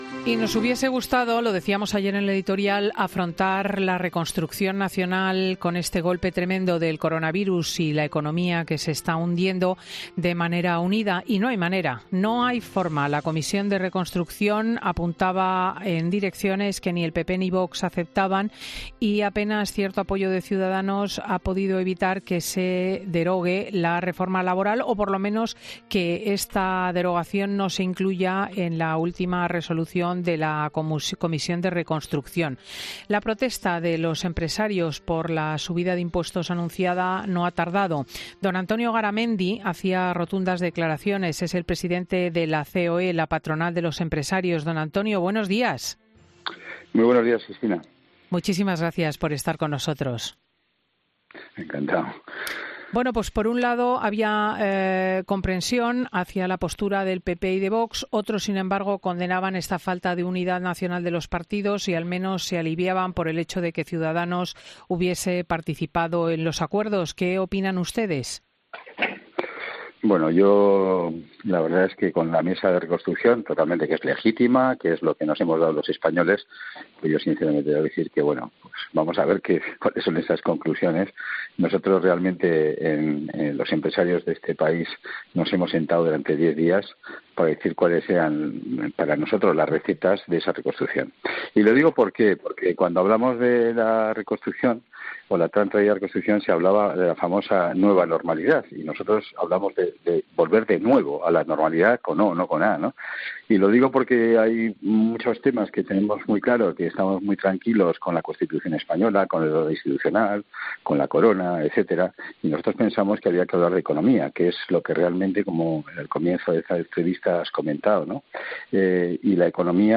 El presidente de la Confederación Española de Organizaciones Empresariales (CEOE), Antonio Garamendi, ha criticado este domingo en Fin de Semana de COPE la última propuesta de Sánchez de hacer una reforma fiscal en España.